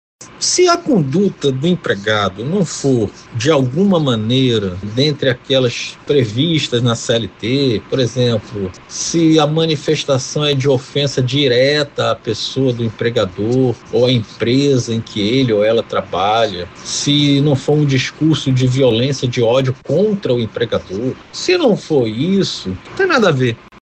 SONORA-JUIZ-GERFRAN.mp3